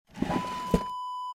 Dresser Drawer Open Wav Sound Effect #1
Description: The sound of a wooden dresser drawer being opened
Properties: 48.000 kHz 16-bit Stereo
A beep sound is embedded in the audio preview file but it is not present in the high resolution downloadable wav file.
Keywords: wooden, dresser, drawer, pull, pulling, open, opening
drawer-dresser-open-preview-1.mp3